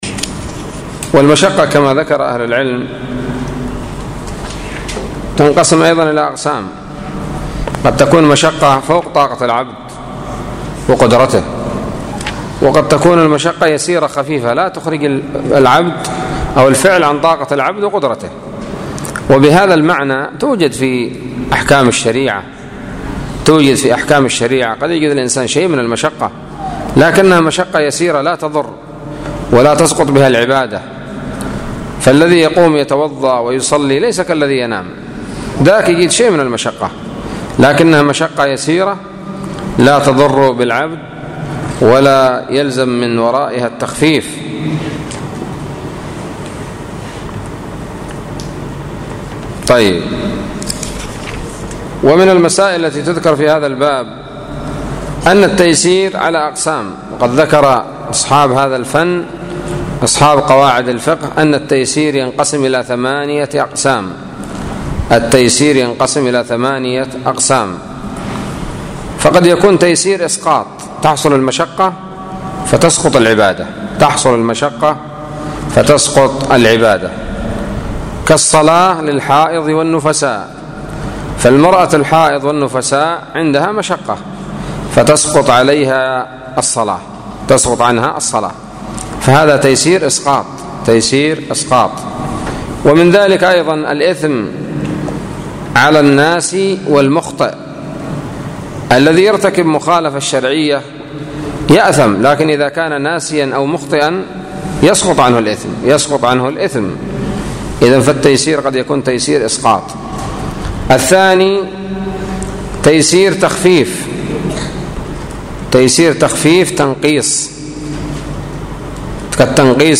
الدرس الثالث عشر من شرح منظومة القواعد الفقهية للشيخ عبد الرحمن السعدي رحمه الله